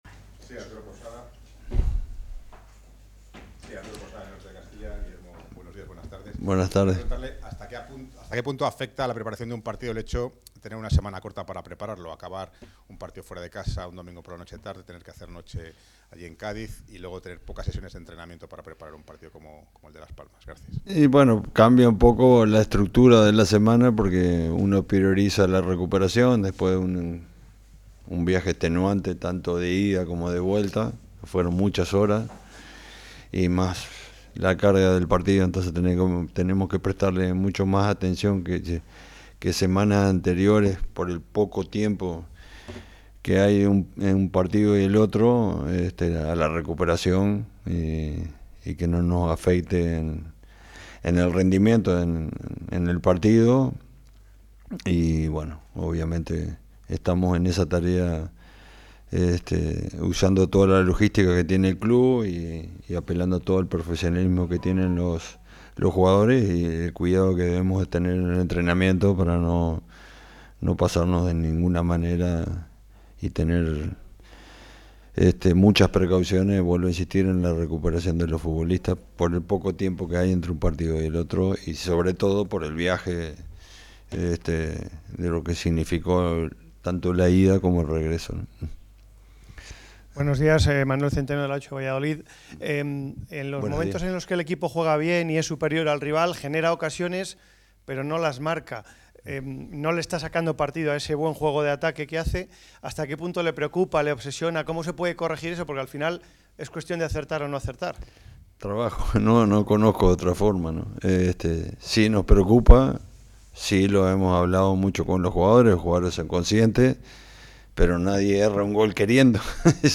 El entrenador uruguayo respondió a las cuestiones de los periodistas.